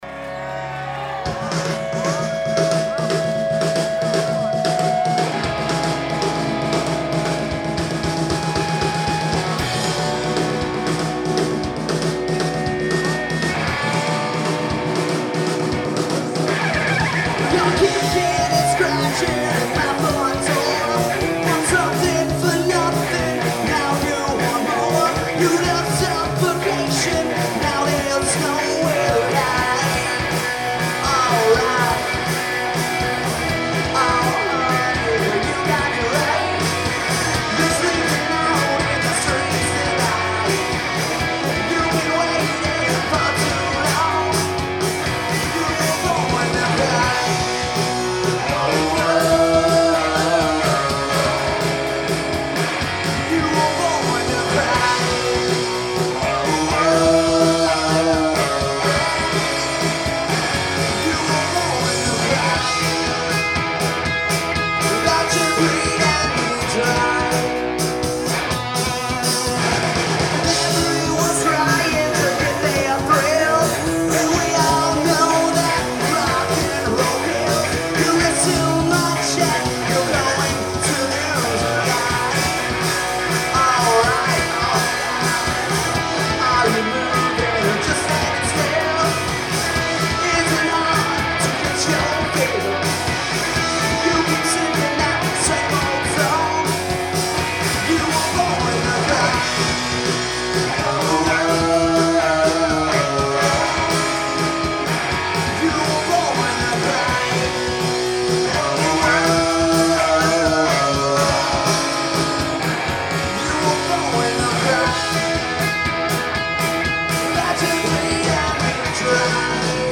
a throw back to straight ahead rock/glam bands